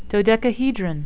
(do-dec-a-he-dron)